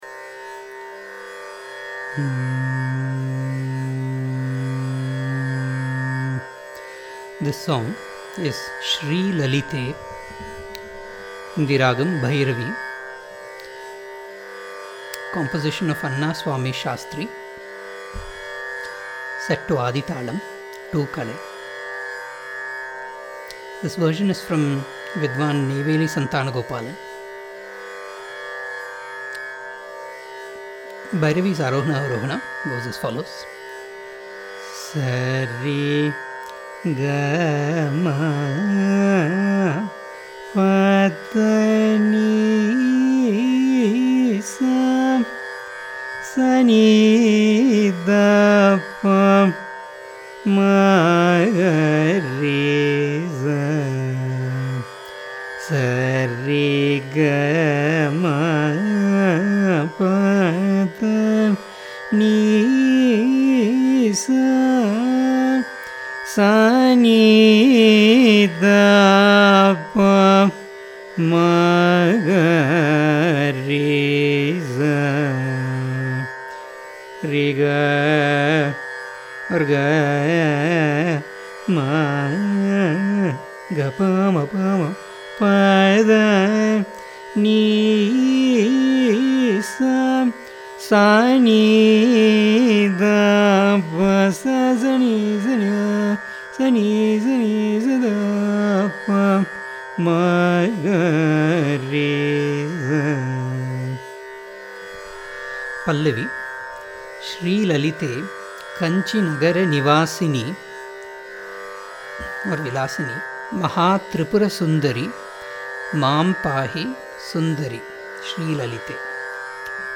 Ragam: Bhairavi �(20th mela janyam)
srilalithe-class.mp3